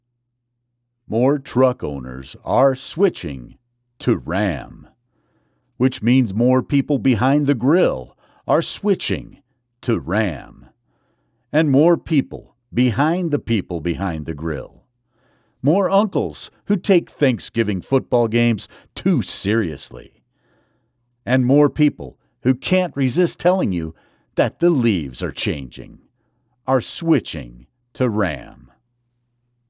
Ram Truck Commercial demo
Ram Trucks Demo.mp3